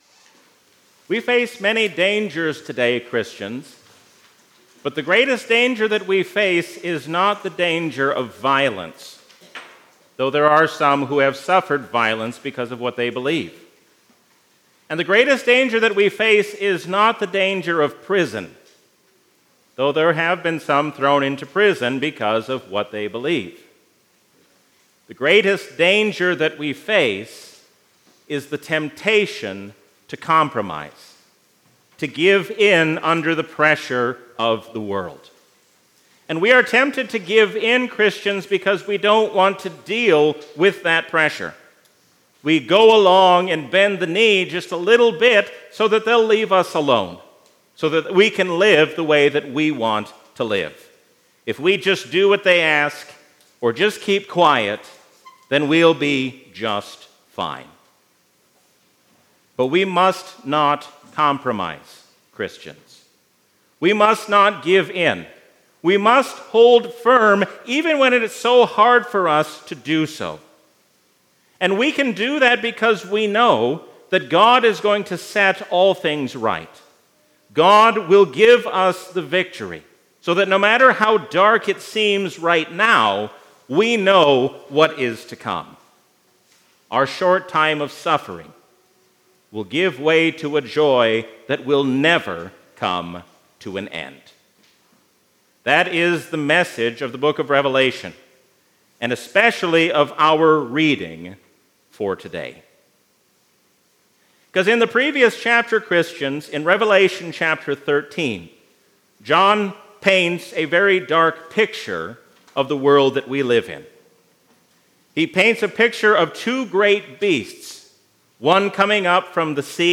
A sermon from the season "Trinity 2022."